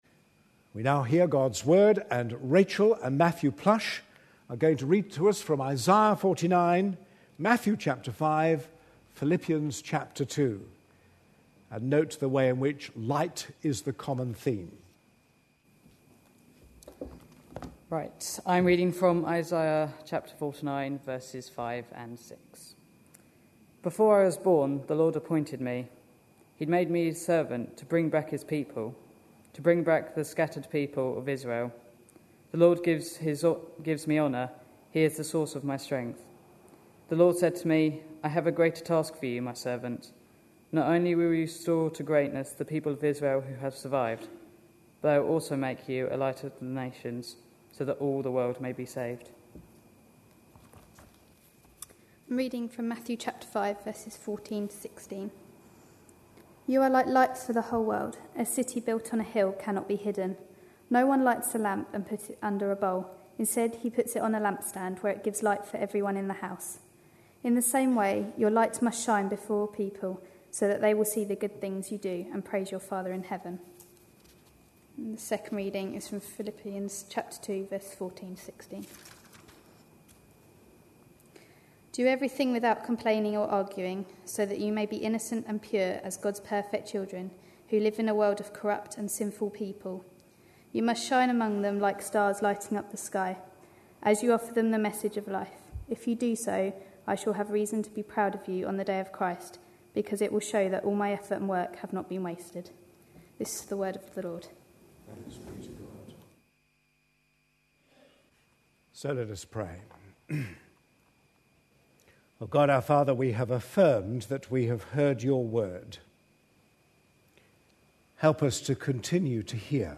A sermon preached on 4th September, 2011.